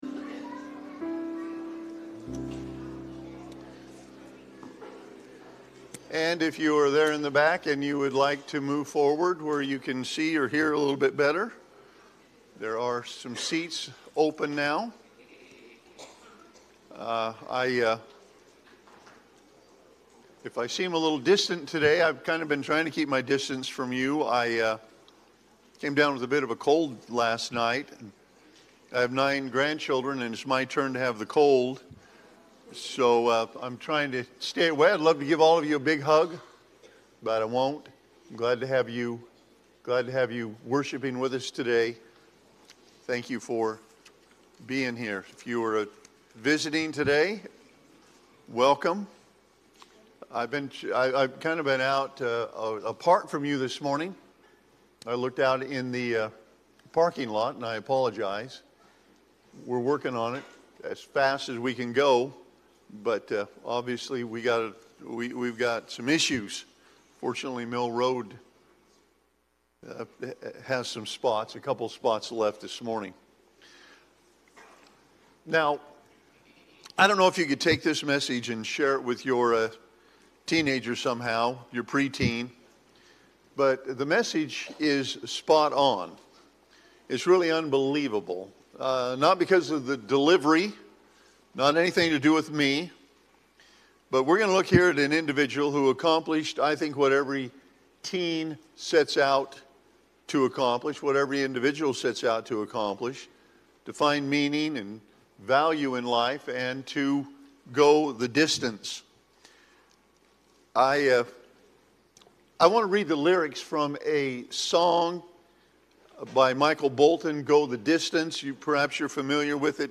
2-15-Sermon-Audio.mp3